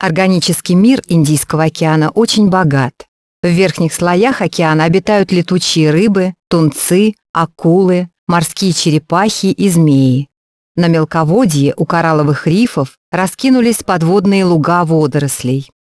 Звуковое сопровождение (Рассказ учителя).